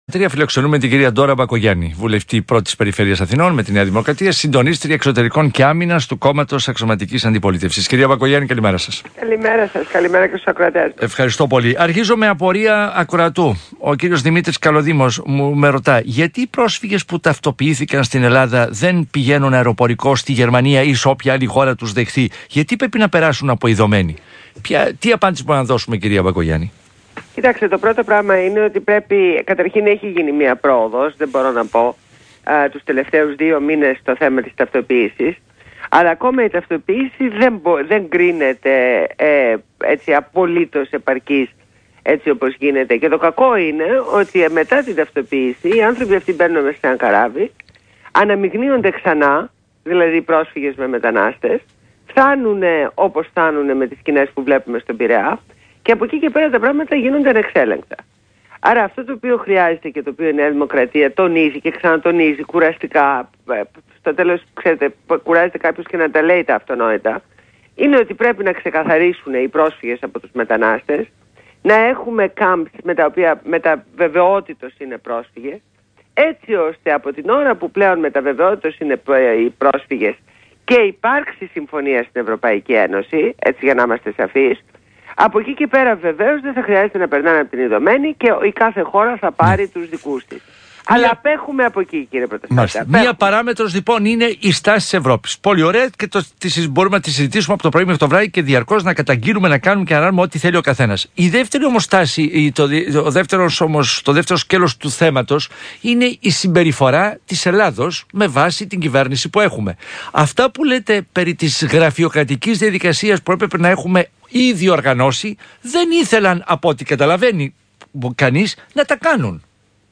Συνέντευξη στο ραδιόφωνο του ΣΚΑΙ